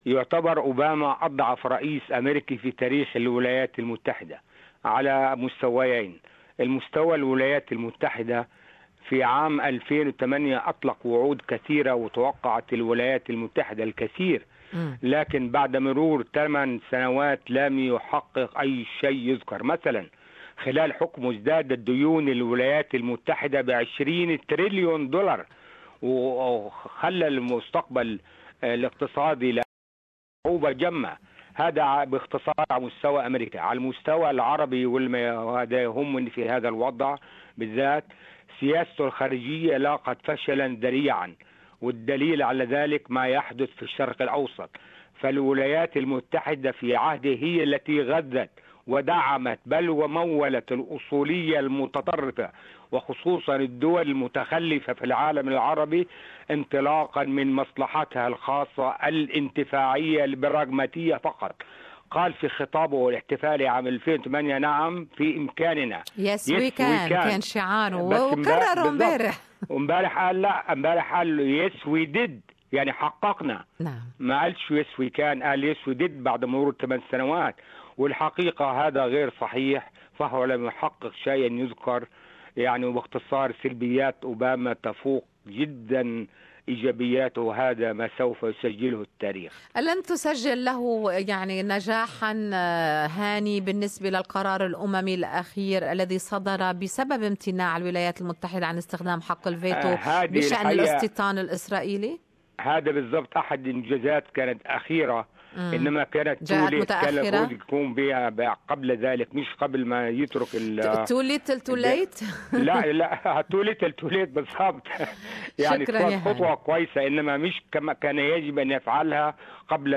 What did Obama achieve during his 8 years in office? Our listeners share their thoughts with SBS Arabic 24.